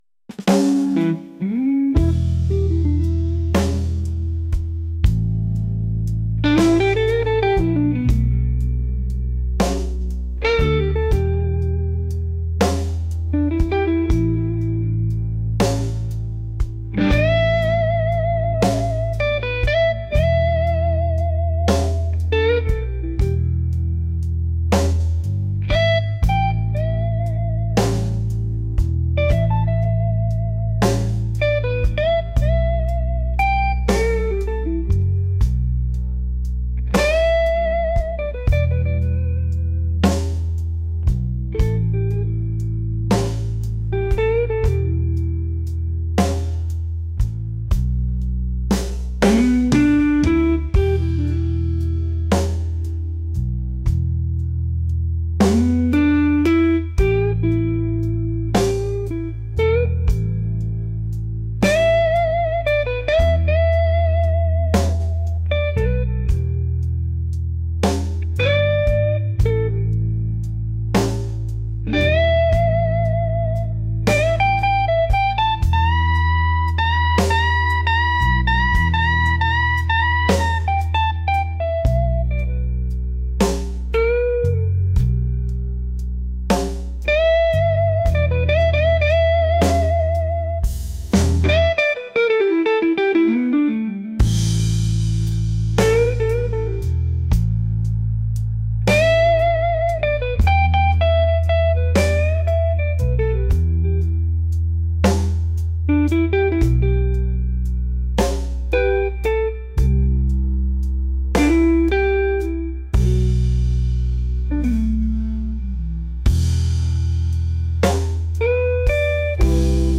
blues | soulful